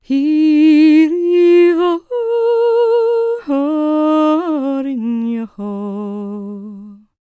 L  MOURN A05.wav